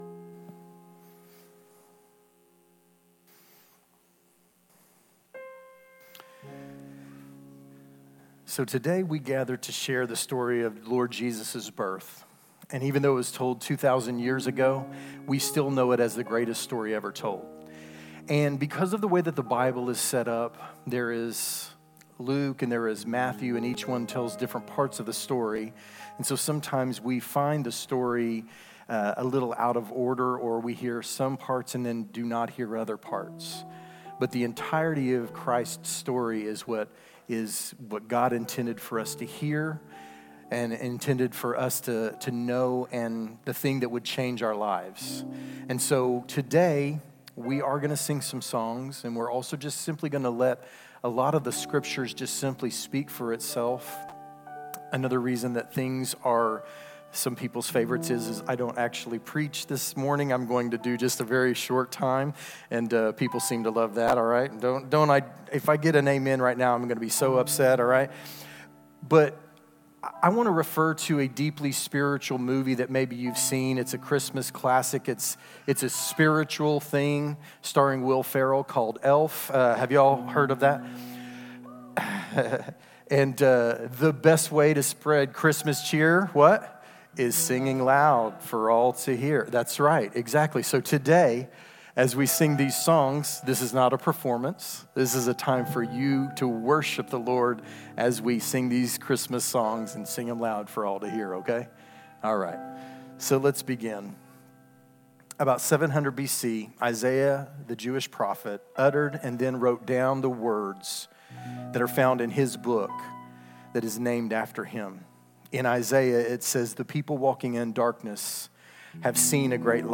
EHC’s Christmas Candlelight Drama